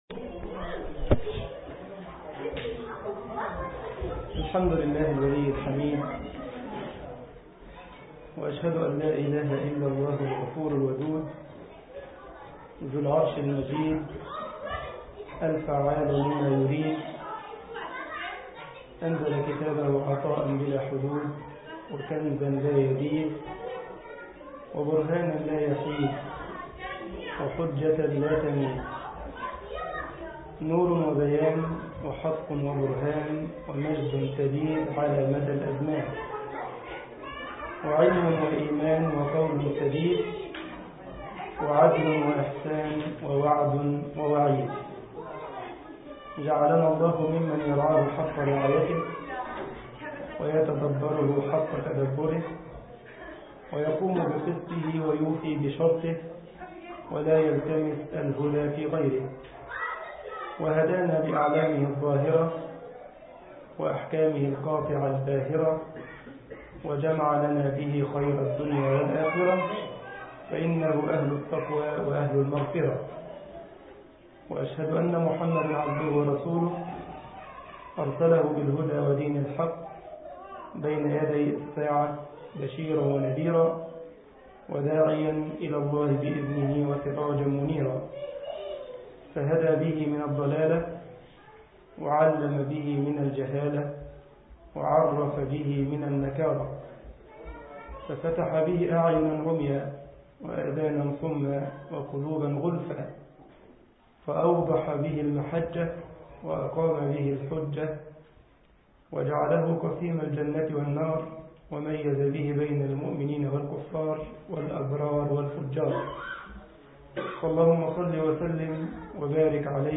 مسجد كايزرسلاوترن ـ ألمانيا محاضرة